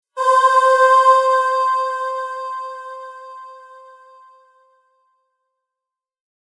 優雅で神聖な雰囲気を漂わせる素敵な声です。アーという声が、幻想的な世界やスピリチュアルな瞬間にぴったりと調和します。